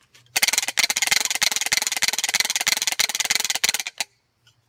Gas Detected Rattle .mp3 {fast: clicking}
~ A mechanical wooden rattle should always be used to sound a Gas Warning (and a cyalume stick used for lighting), because it does not produce a spark.
Gas_Detected_Rattle.mp3